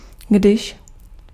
Ääntäminen
Synonyymit ifall såvida huruvida därest Ääntäminen Tuntematon aksentti: IPA: /ɔm/ Haettu sana löytyi näillä lähdekielillä: ruotsi Käännös Ääninäyte Konjunktiot 1. když 2. jestliže 3. pokud 4. zda 5. jestli 6.